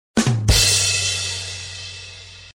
Badum Tss Meme Effect Sound sound effects free download